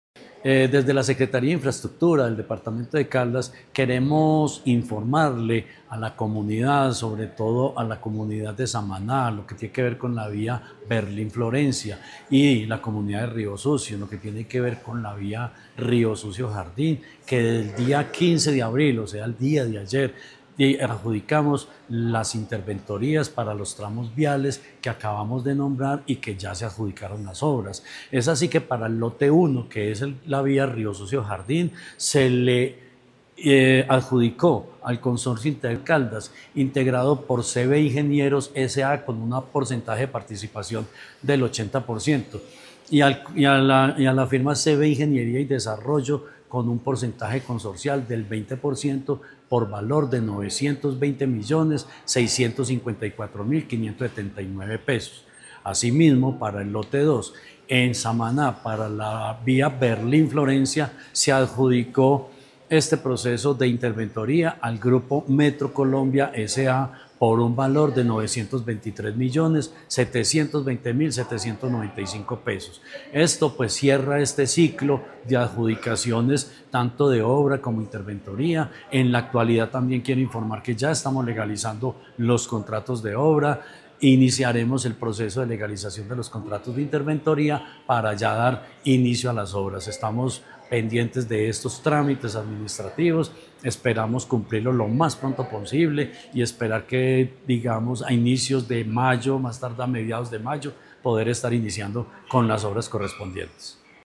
Secretario de Infraestructura de Caldas, Jorge Ricardo Gutiérrez Cardona.